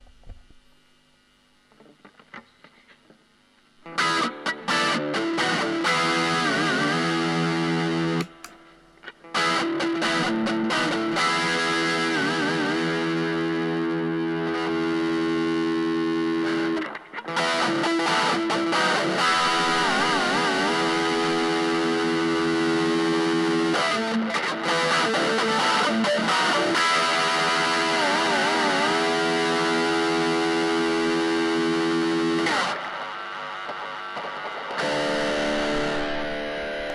今回は下の方にあるように、アンプで鳴らしてマイク録音してます。
ブースターっぽい使い方で、アンプのリードチャネルを使ってます。OFF、Gainゼロ、Gain50%、Gain75%と変化させてみました。
TASCAMのGT-R1のマイク録音です。